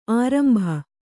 ♪ ārambha